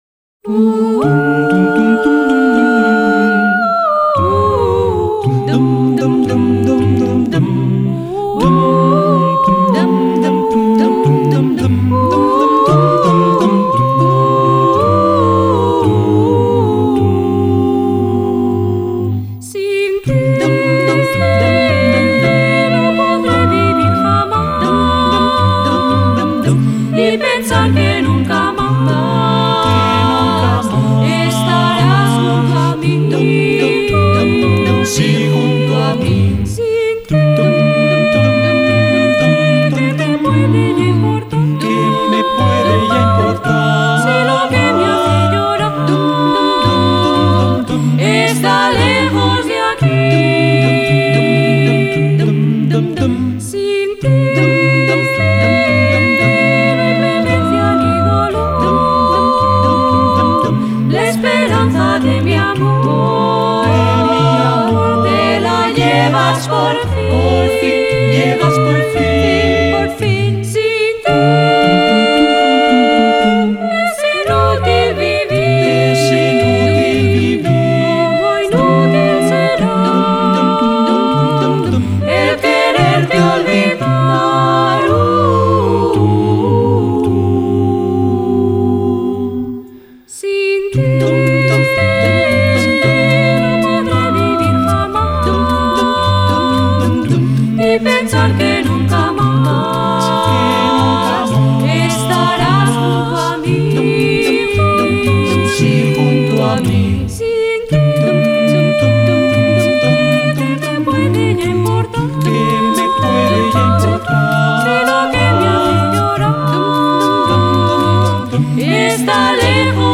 a vocal band